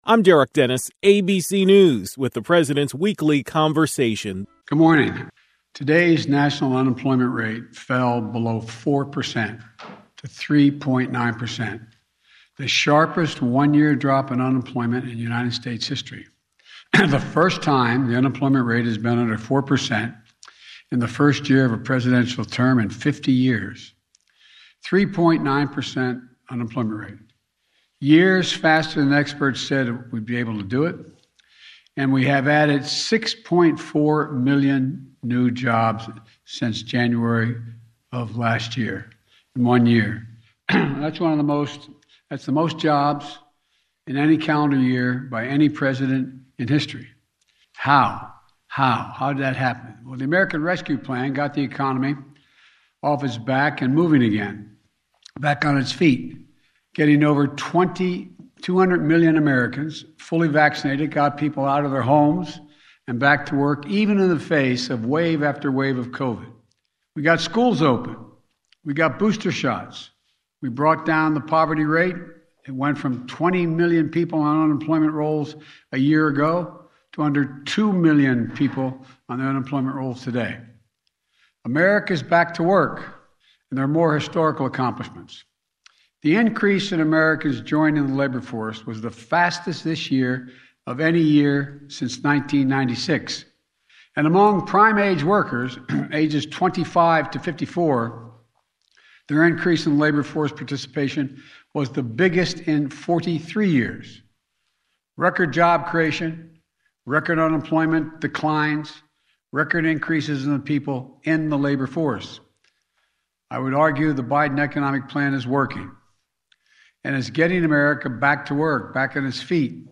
Biden was Monday’s KVML “Newsmaker of the Day”. Here are his words: